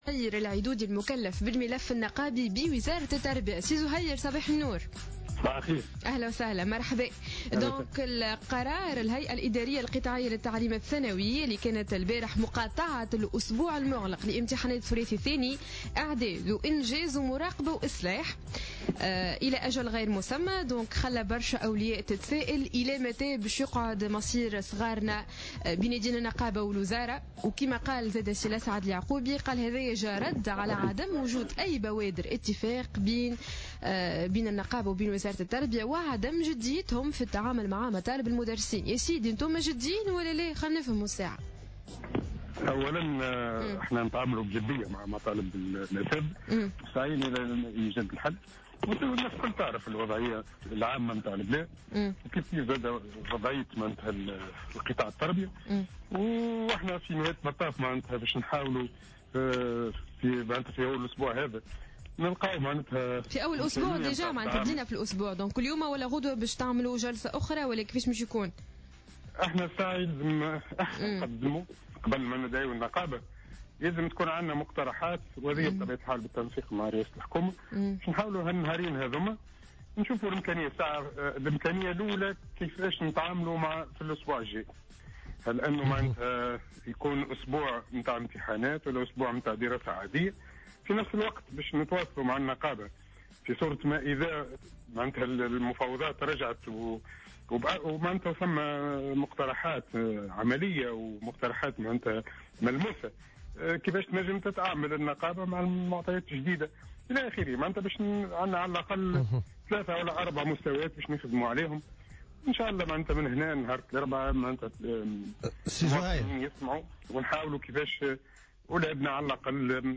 في مداخلة له على جوهرة "اف ام" صباح اليوم الاثنين 23 فيفري 2015